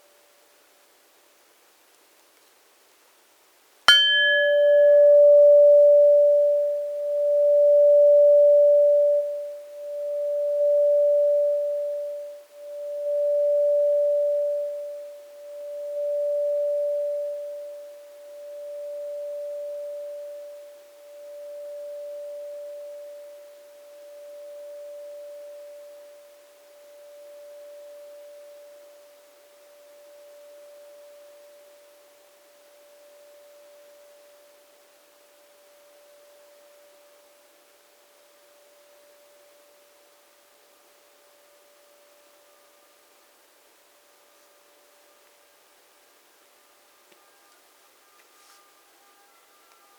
We repeat the previous measurements, same microphone, same software, same drumstick, preferably in the same room and at the same time.
The spectrum after damping is lower in level but richer in harmonics, which in any case are reduced to less than 3 seconds compared to about 40 originally, listen to the two sounds.
PX2-hit-platter-50s.mp3